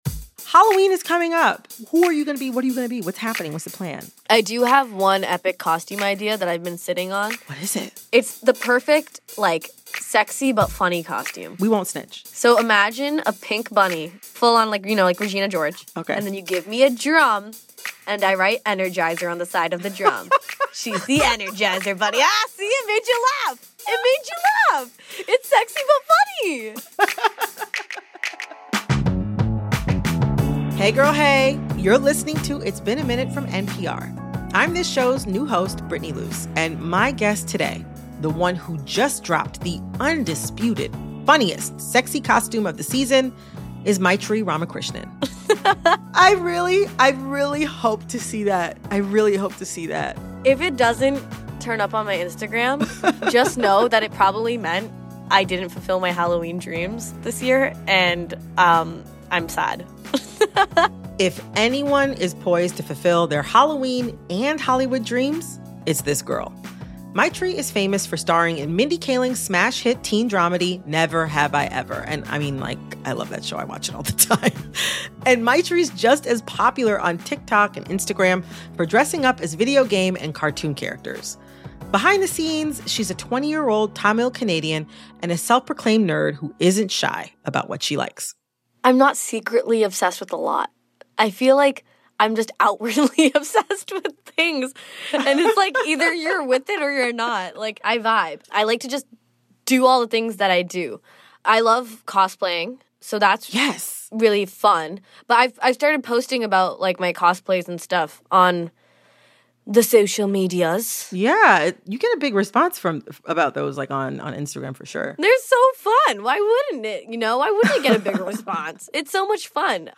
Host Brittany Luse gets Ramakrishnan dishing on everything from cosplay to car chases. They also talk about Ramakrishnan's wildest dream role, how to deal with haters and why she hopes this is just the beginning – for her, and for other nerdy brown girls who want to make it in Hollywood.